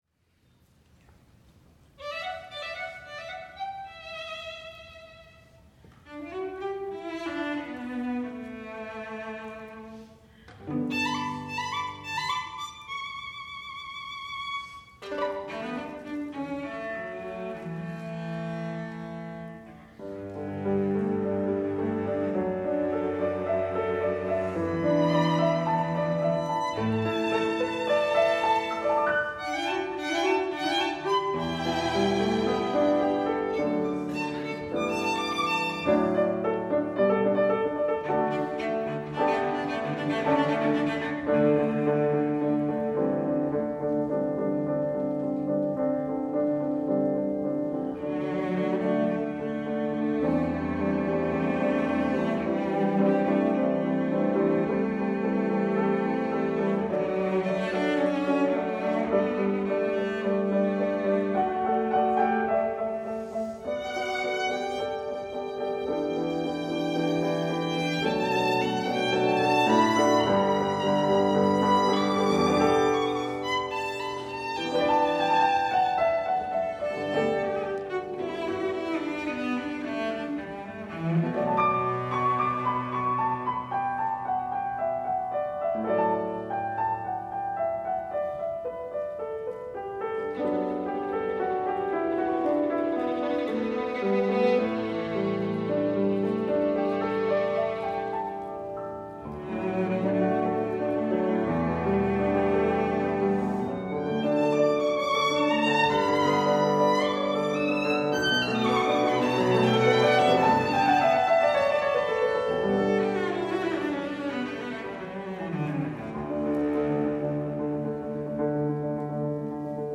for Piano Trio (2015)